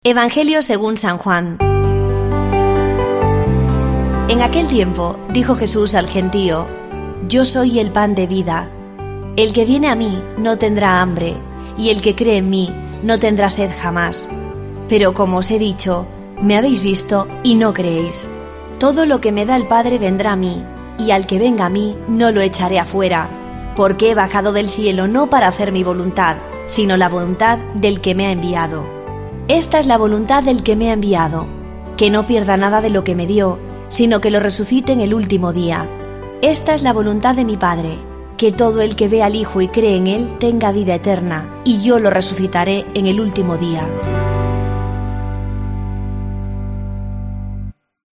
Lectura del santo Evangelio según san Juan 6, 35-40